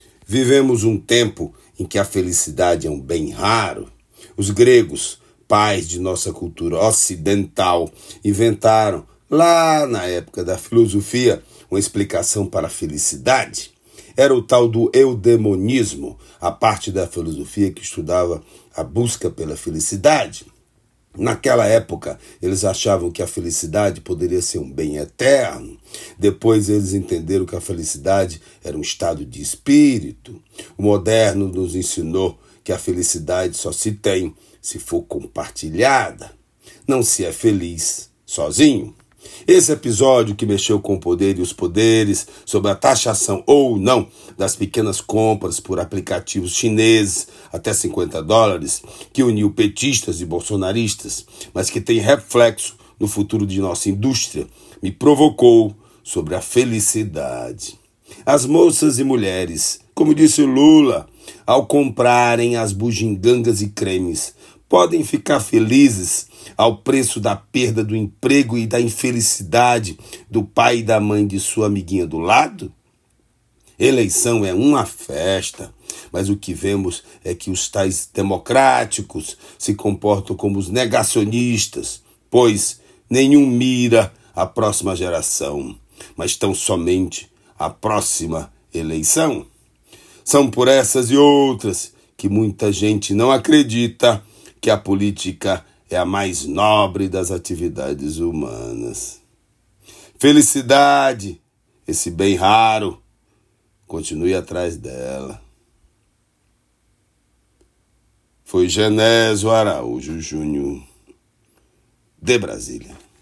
direto de Brasília, especialmente para OgazeteirO.